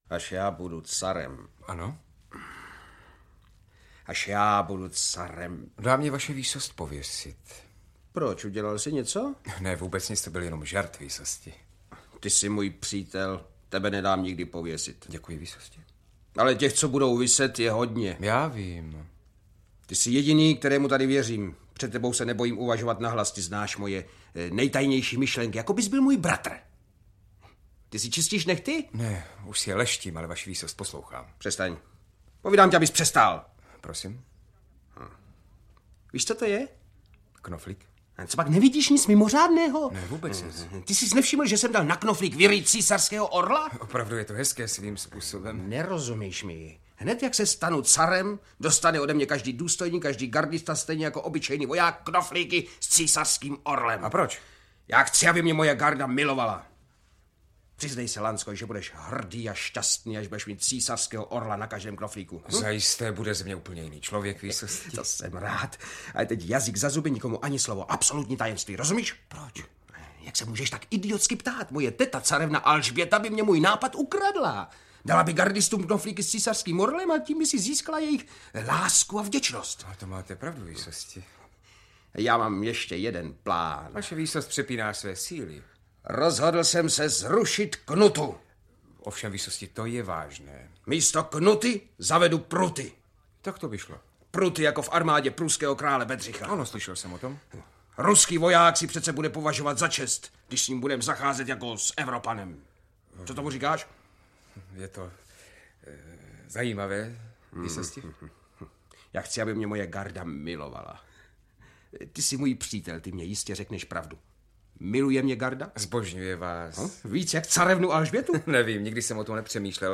Album scén z divadelních her 3 (Savoir, Jariš, Giraudoux) - Alfred Savoir - Audiokniha
• Čte: Otakar Brousek st., Radoslav Brzobohatý…